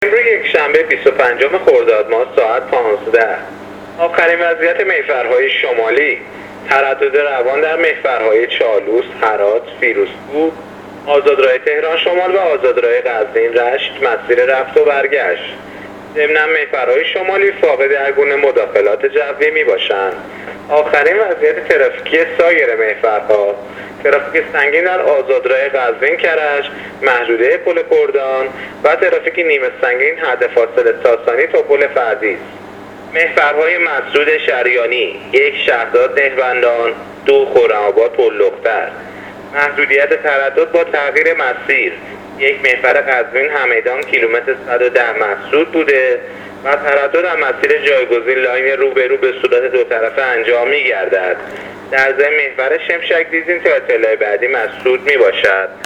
گزارش رادیو اینترنتی از وضعیت ترافیکی جاده‌ها تا ساعت ۱۵، بیست و پنجم خرداد